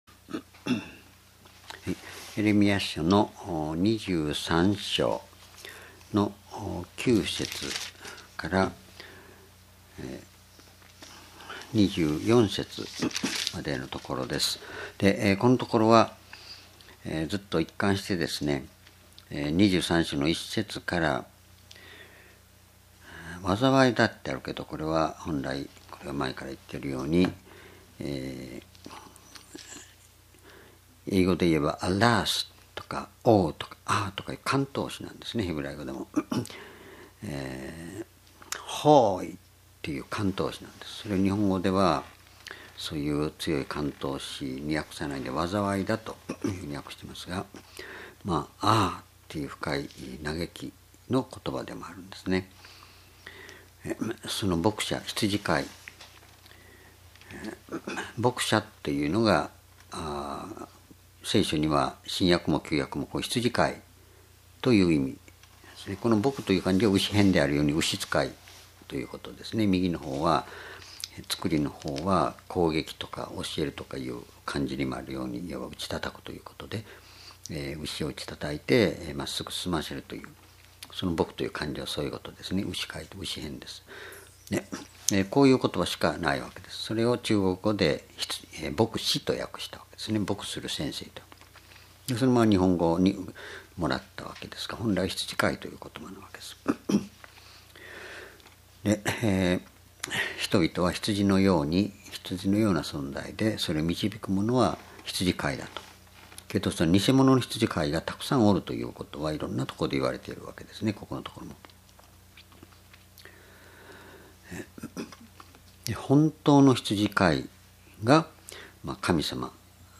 主日礼拝日時 2016年12月6日 夕拝 聖書講話箇所 「天地を満たしている主」 エレミヤ書23章9-24 ※視聴できない場合は をクリックしてください。